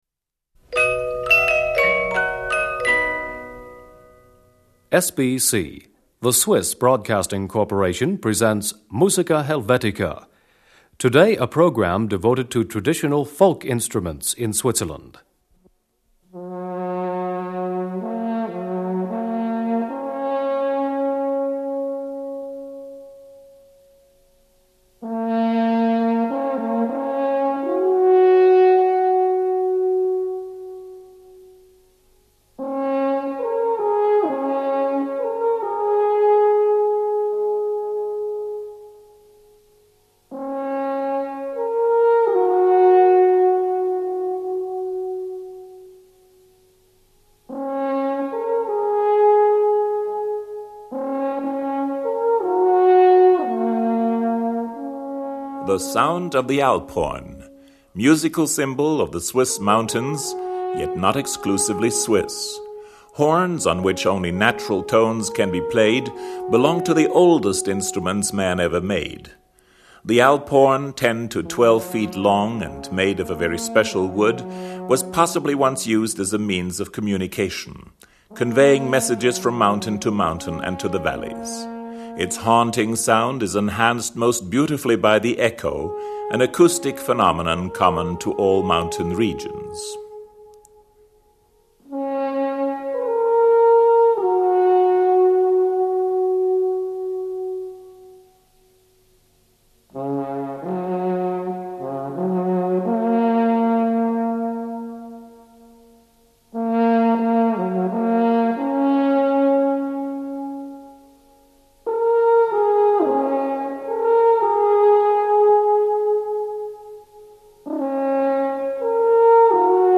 Swiss Folk Music. Traditional Instruments.
Alphorn Solo. Improvisation.
Traditional march.
zither. 5. Arabi Trad. Drummers and fifers of the Basel Lälli-Clique.
Trad. Original Appenzell String Band. 8.
Trad. Riva San Vitali Mandolin Duo.